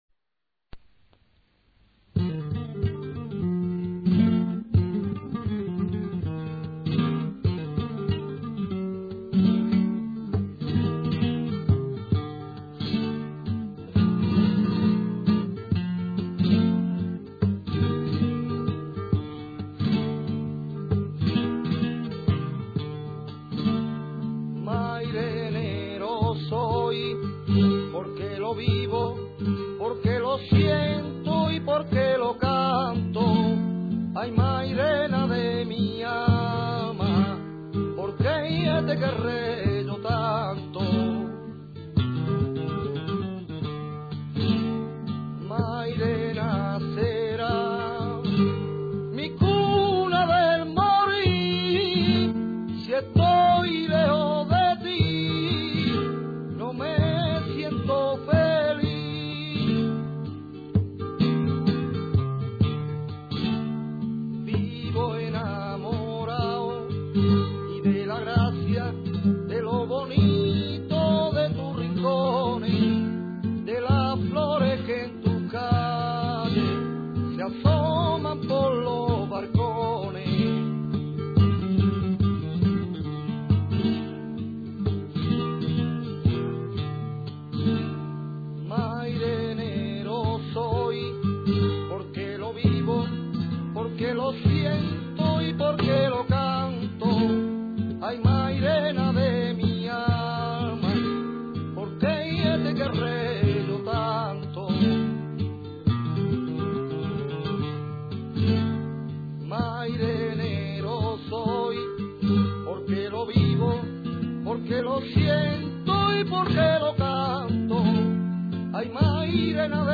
Tangos
Sonido MP3 grabaciones de Radio Mairena FM. y CD Por Mairena